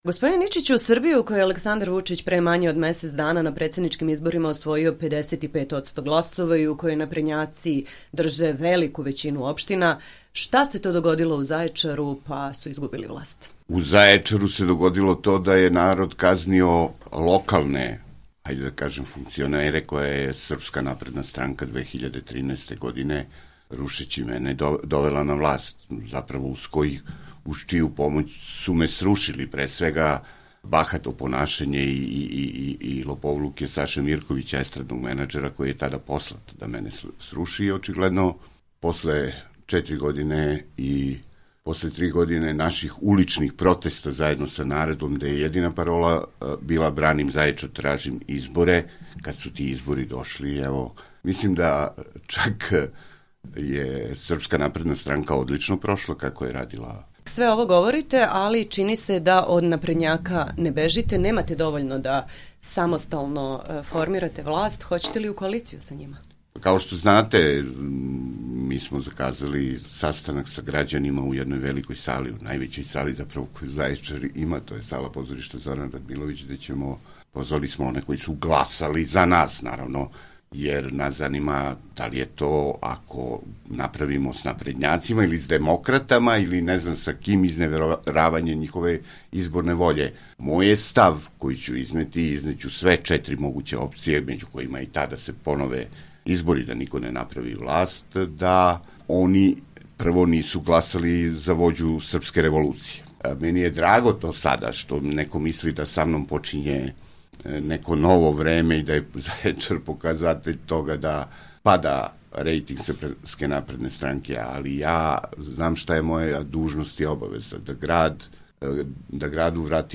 Intervju: Boško Ničić
Druga opcija je saradnja sa koalicijom okupljenom oko Demokratske stranke, koju čini i Liberalno demokratska partija. U Intervjuu nedelje RSE, Ničić govori o tome zašto su mu ovi bivši partneri neprihvatljivi.